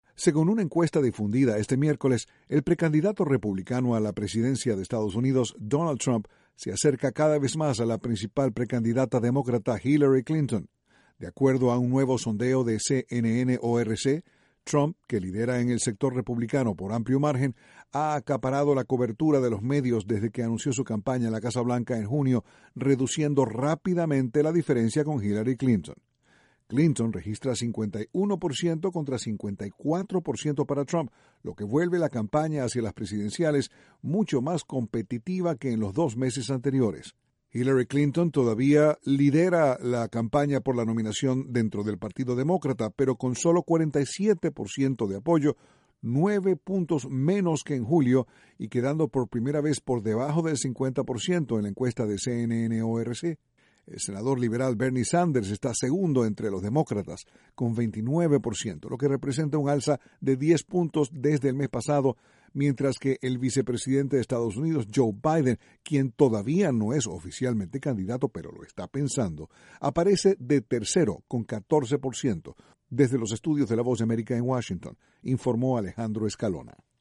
Donald Trump sigue subiendo en las encuestas, a pesar de sus comentarios sobre las mujeres y los inmigrantes. Desde la Voz de América, Washington